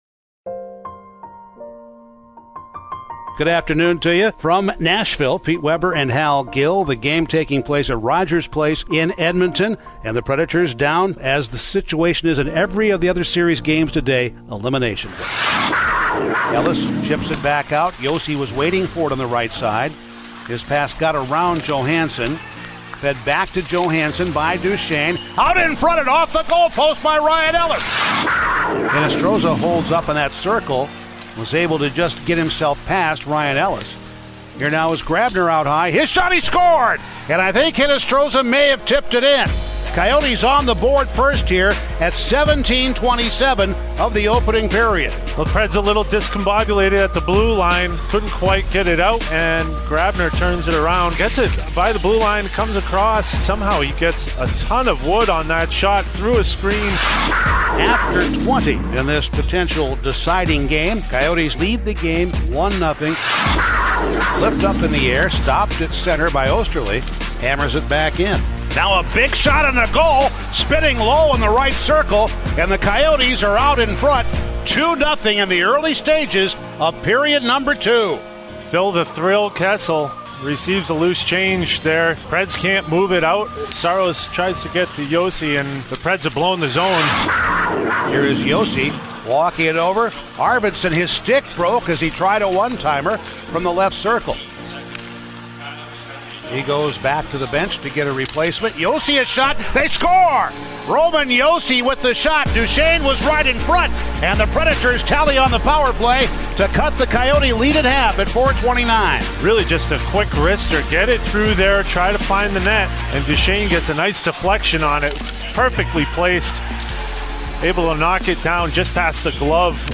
Full radio highlights from the Preds 4-3 overtime loss to the Coyotes to end the season in Game 4 of the Qualifying Round on August 7, 2020.